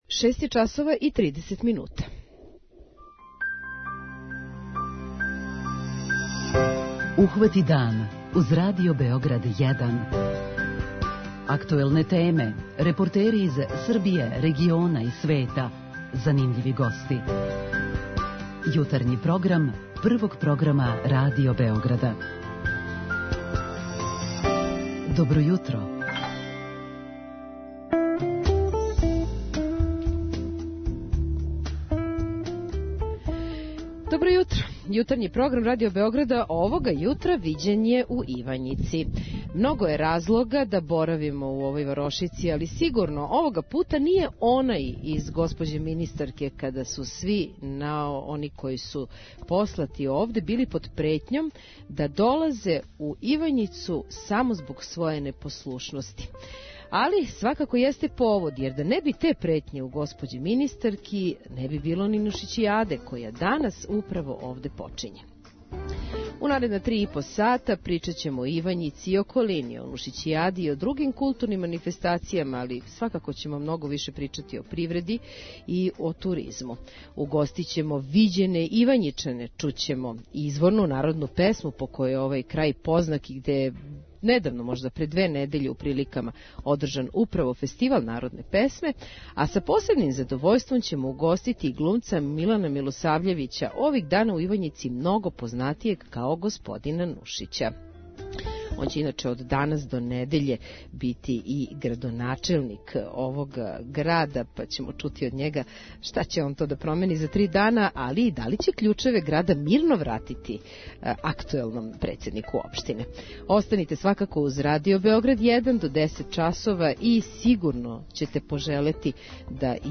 Од шест и тридесет до десет у нашем импровизованом студију у ресторану у центру Ивањице, угостићемо све оне који ће нас упознати са овим крајем наше земље. Причаћемо о развоју општине, о узгоју малина и кромпира, о туристичким потенцијалима. Чућемо изворно народно певање, а посебан део емисије посветићемо управо Нушићијади и разлозима зашто се ова манифестација одржава баш овде.